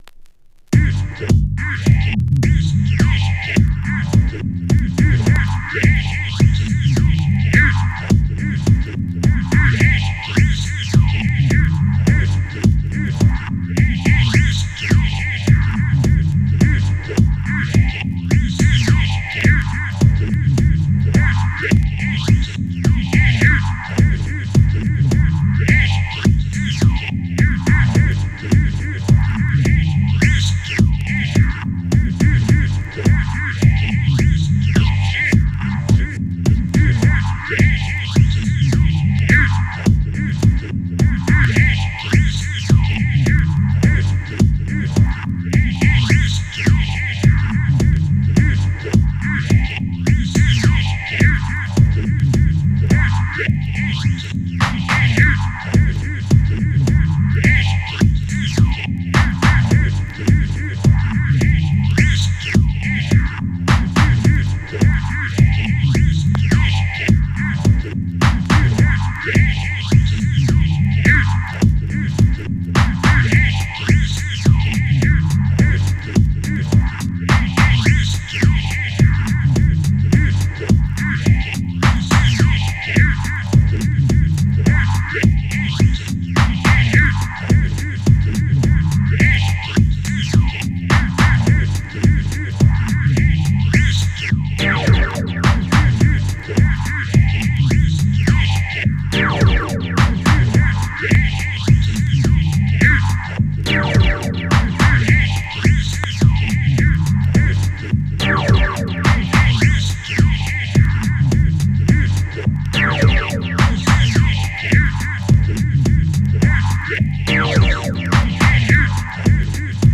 ファットなリズム・ワークと絶妙なループで蘇らせた
ミキシングやエフェクトで抑揚をつけたマッドなディスコ・ブギー